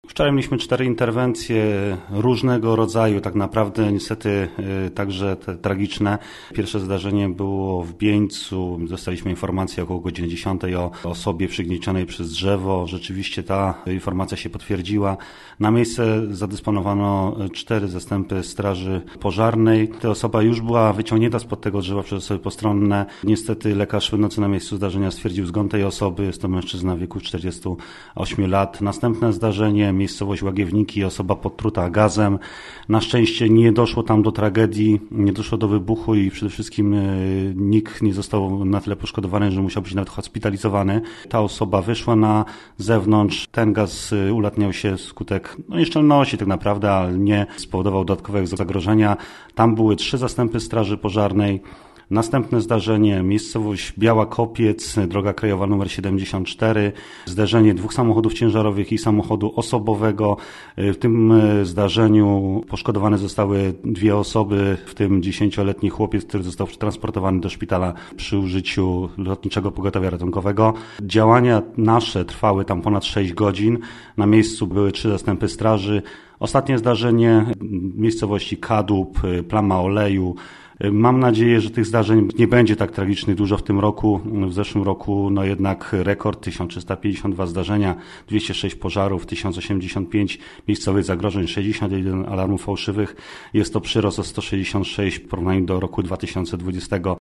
mówił nam w wywiadzie